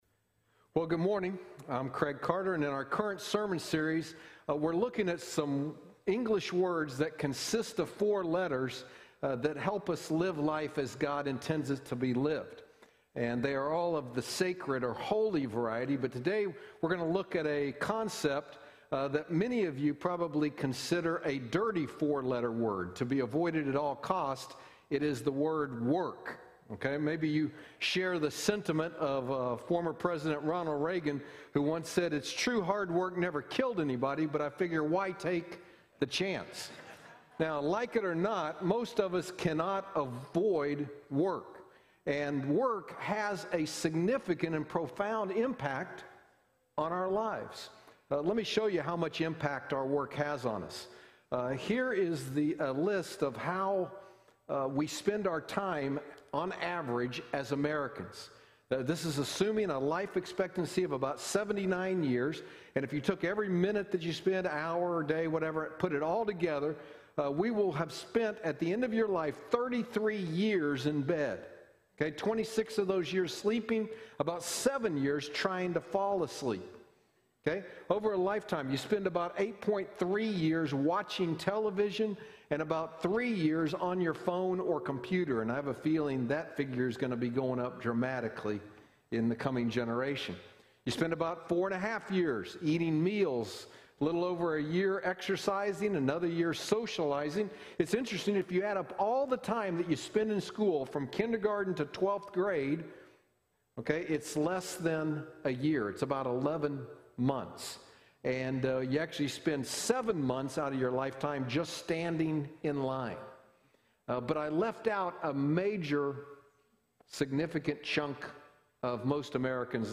Sacred 4-Letter Words Service Type: Sunday Morning Download Files Notes Bulletin « Sacred 4-Letter Words